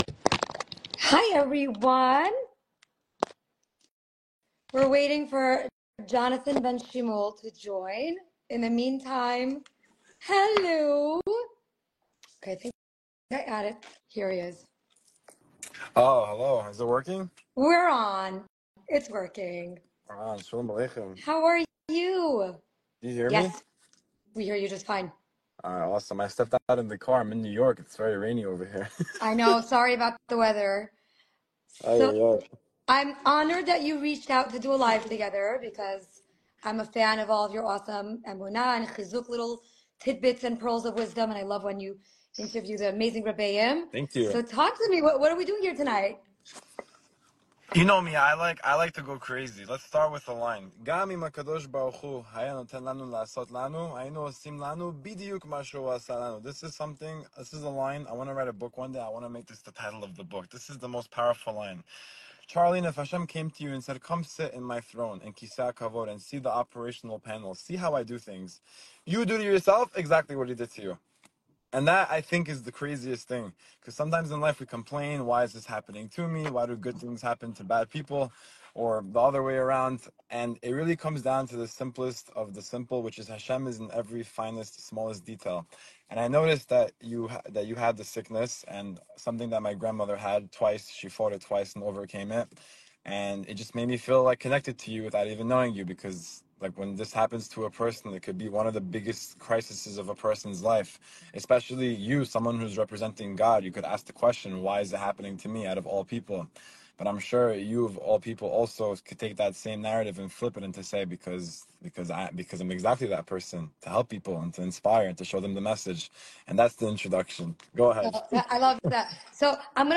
Finding Meaning & Our Purpose In Life- Class In Queens